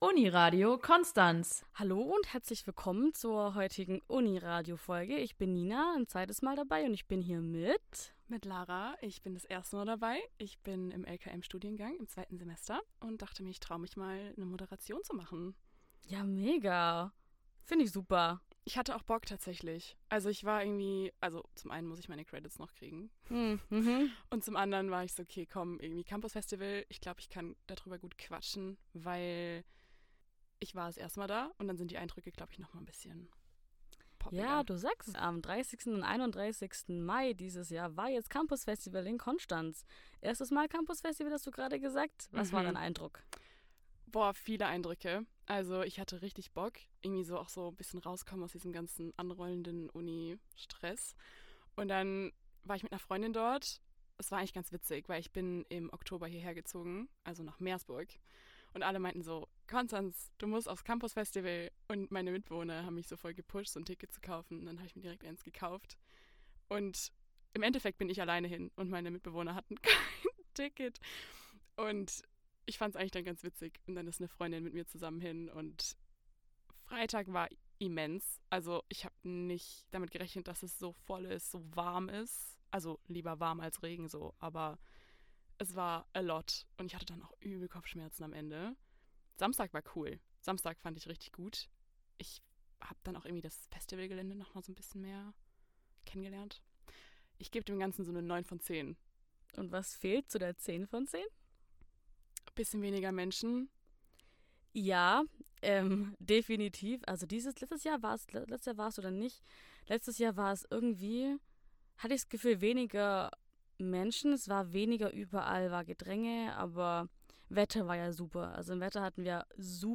Folge4_Schnitt_mono-2.mp3